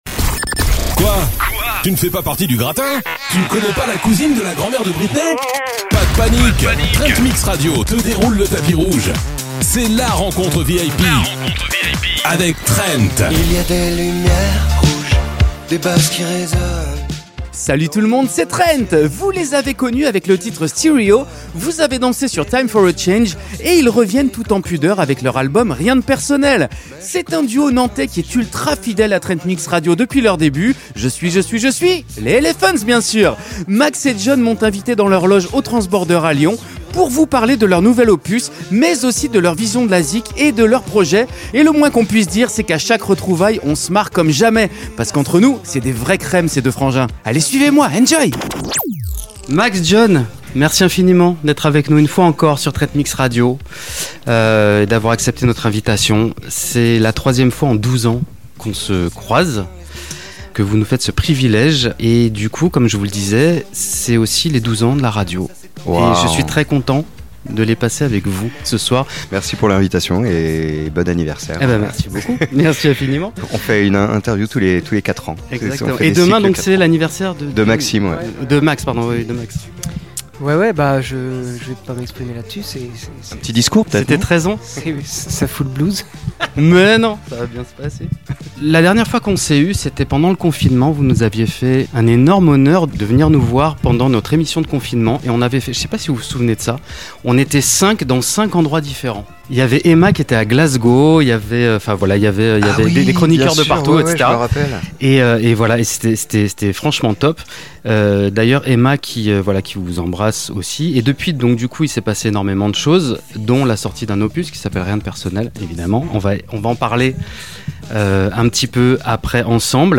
Les Elephanz nous ont invités en loges avant leur live, au Transbordeur à Lyon, pour un moment unique.